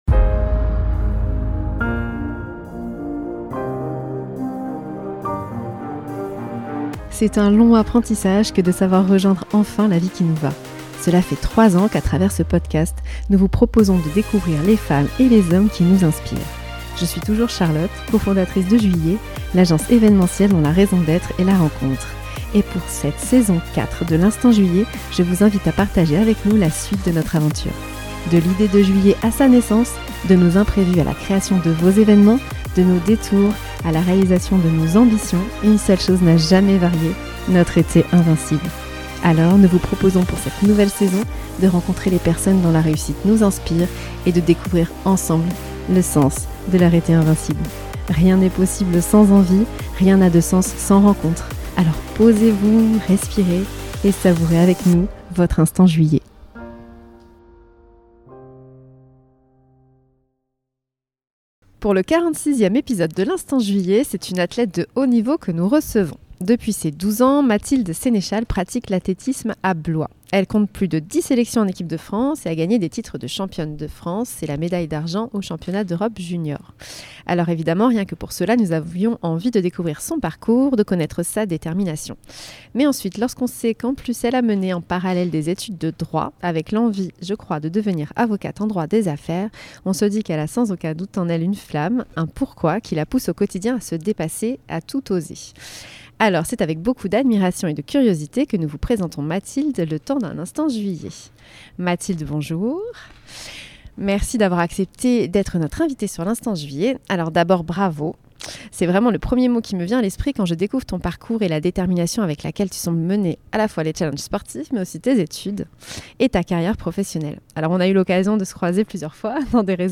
Pour ce 46ème épisode, c’est une athlète de haut niveau que nous recevons.